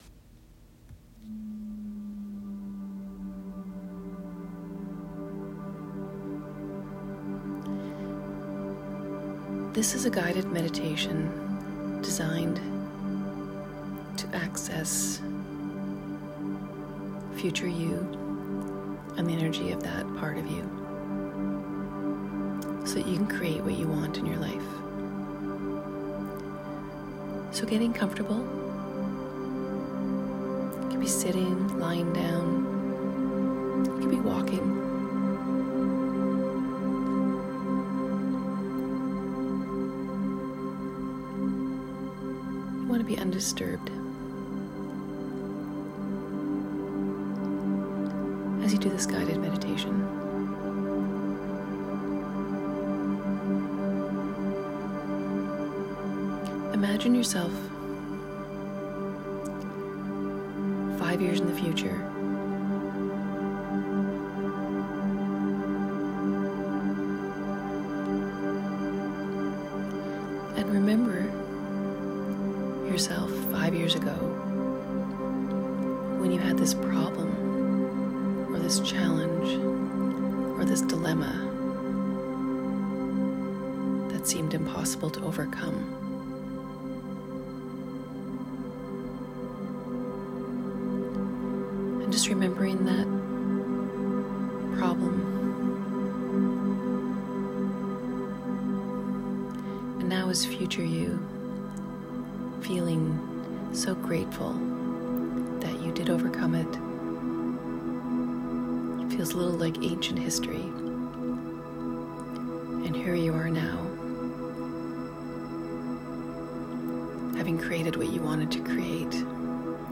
Future You - A Guided Meditation